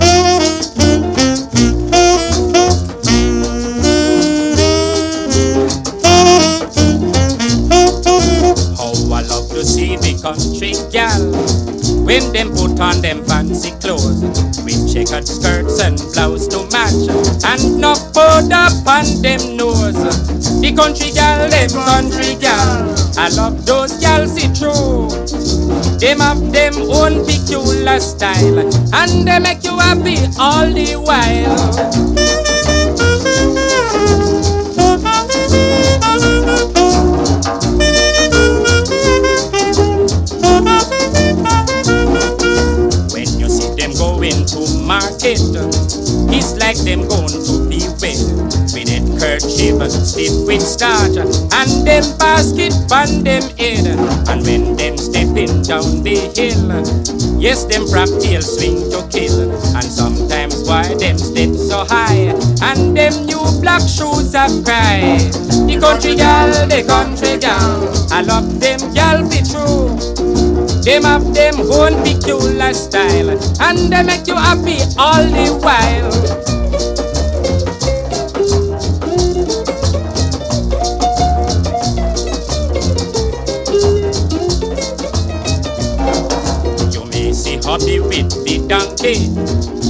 INDIE POP
BREAKBEATS / ELECTRONICA